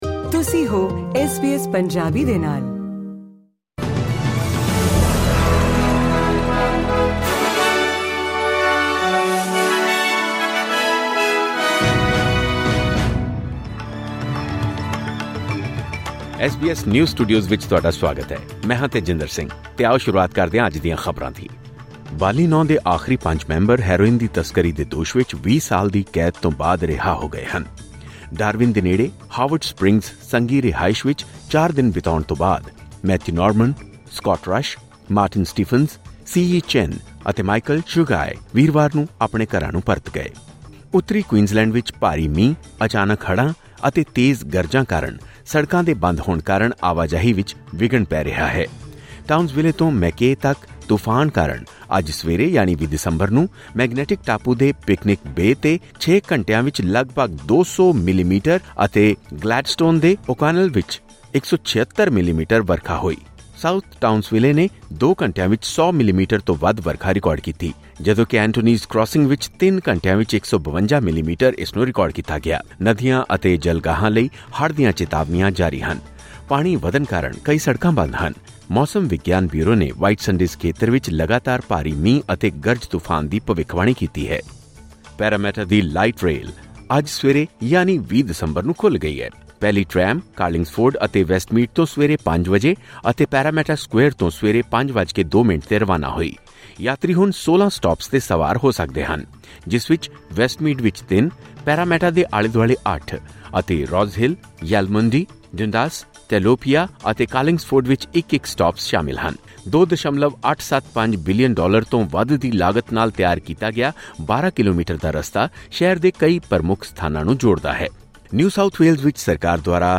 ਖਬਰਨਾਮਾ: ਉੱਤਰੀ ਕੁਈਨਜ਼ਲੈਂਡ ਵਿੱਚ ਭਾਰੀ ਮੀਂਹ ਕਾਰਨ ਆਵਾਜਾਈ ਵਿੱਚ ਵਿਘਨ